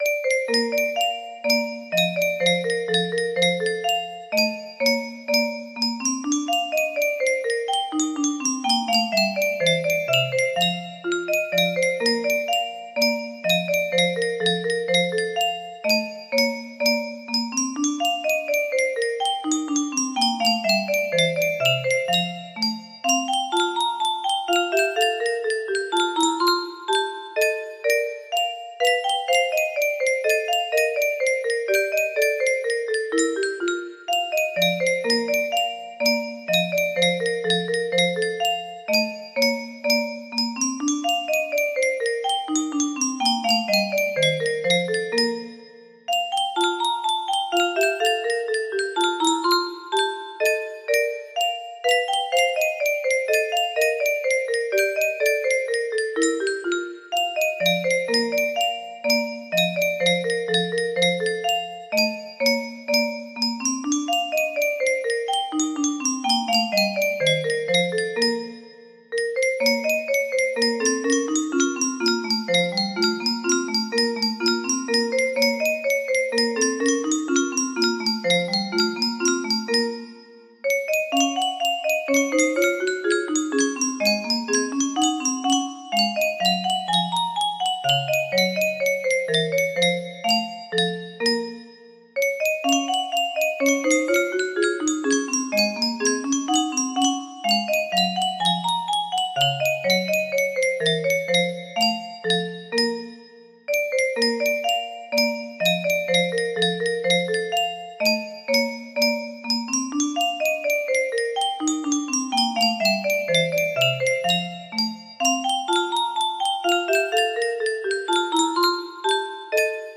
Sonat music box melody
Full range 60